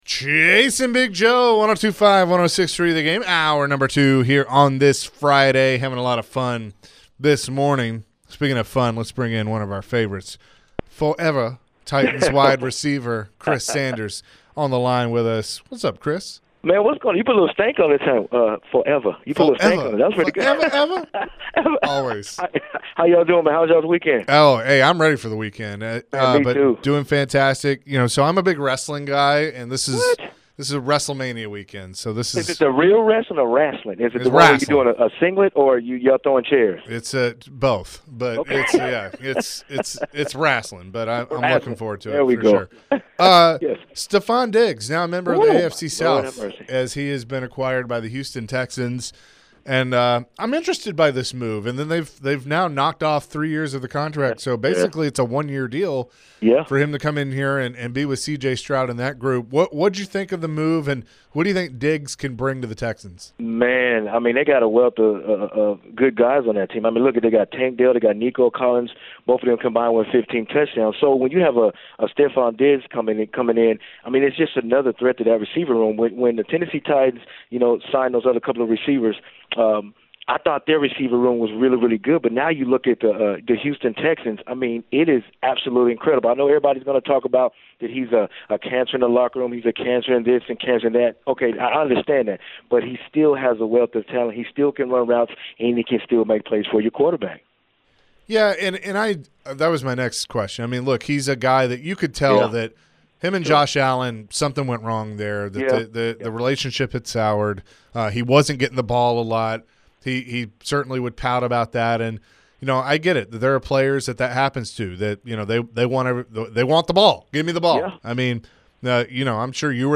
Forever Titans wide receiver Chris Sanders joined the show and shared his thoughts on the Stefon Diggs trade to the Houston Texans. Chris also mentioned what this means for the Tennessee Titans.